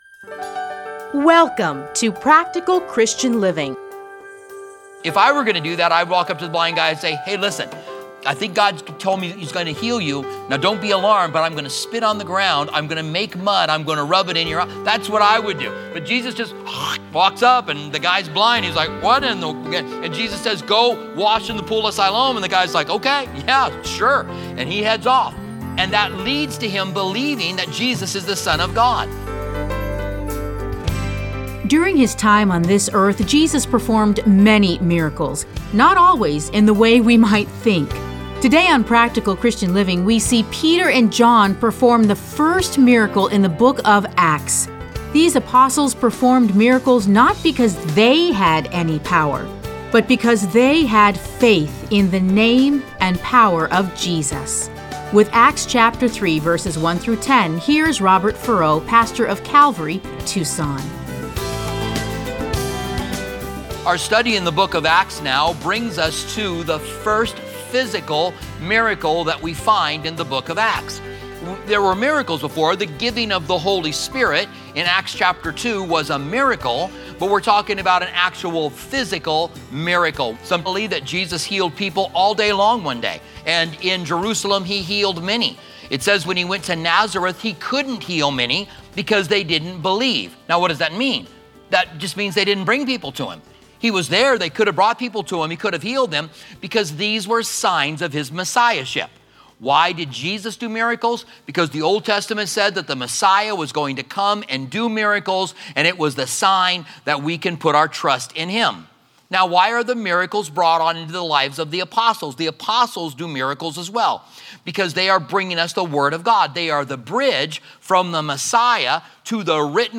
Listen to a teaching from Acts 3:1-10.